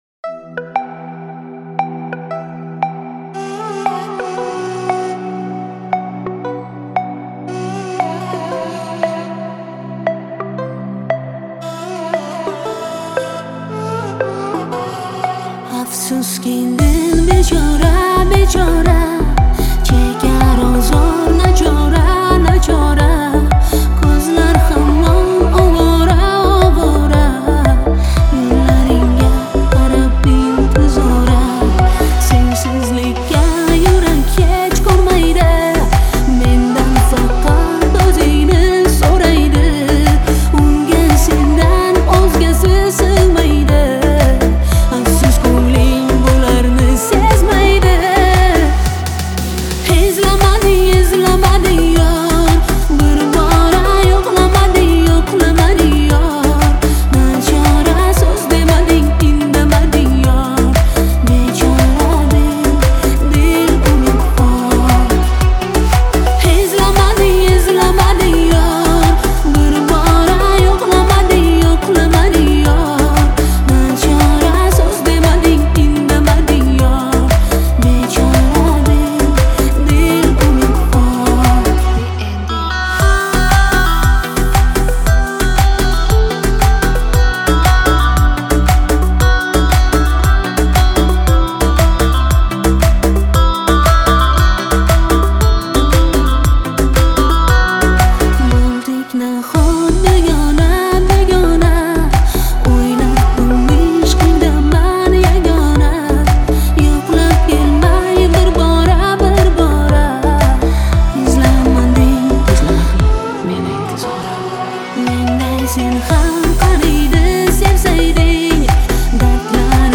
обладая мощным вокалом